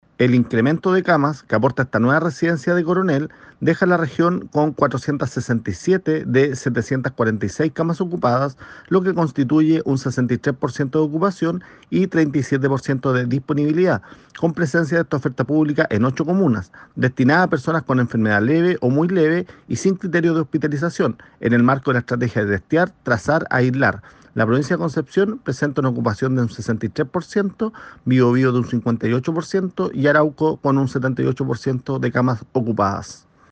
En tanto, el Seremi de Salud del Biobío, Héctor Muñoz, detalló cómo esta medida se verá reflejada en el aumento de camas disponibles para personas diagnosticadas con Covid-19 y que no requieren hospitalización.